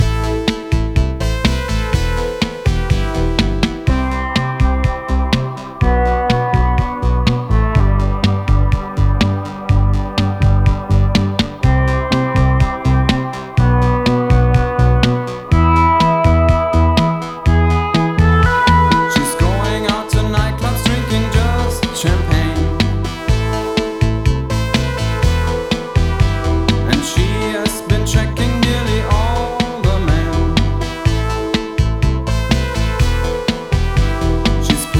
Жанр: Рок / Электроника / Классика